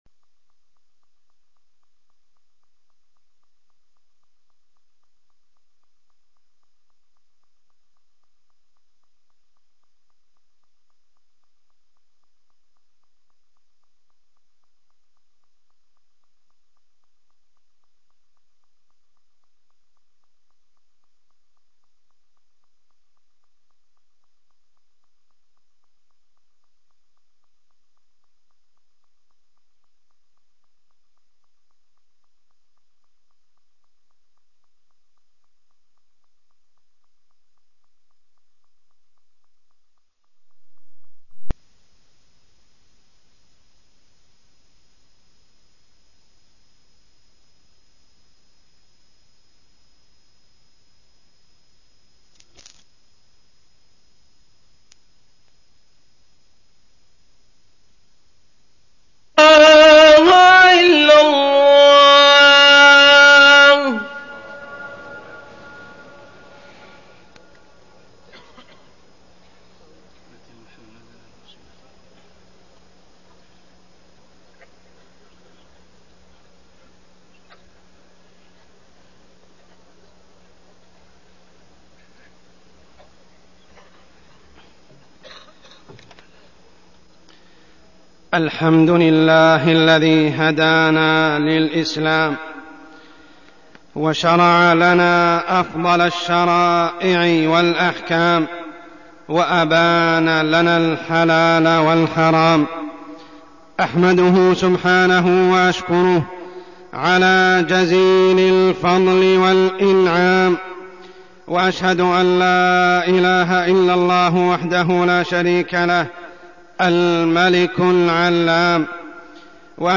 تاريخ النشر ٢٣ شوال ١٤١٨ هـ المكان: المسجد الحرام الشيخ: عمر السبيل عمر السبيل النظام المالي الإسلامي